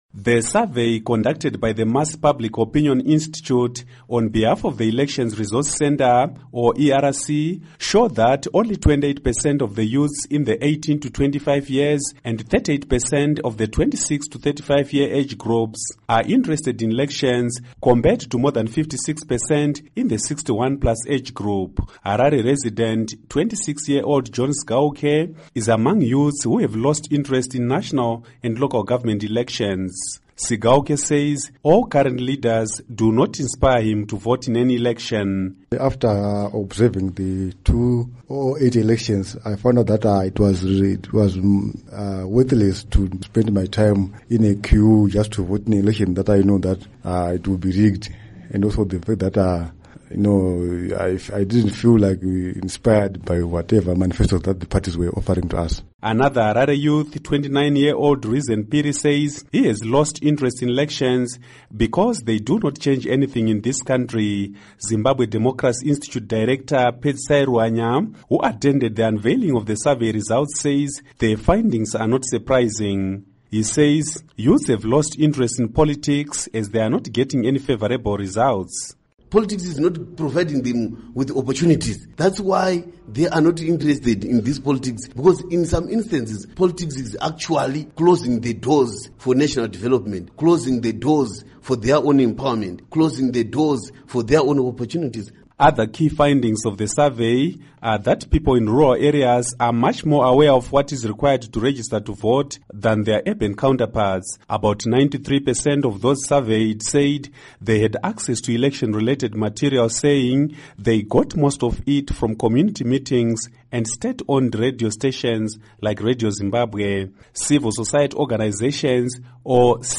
Report on Youth and Zimbabwe Elections